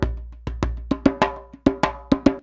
100DJEMB20.wav